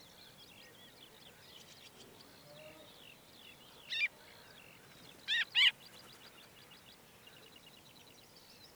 Cotovía cristada
Canto
O seu canto, composto de trinos rápidos e notas complexas, é unha das características máis chamativas das paisaxes rurais e agrícolas nas que habita.